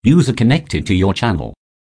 user.joined.connect.wav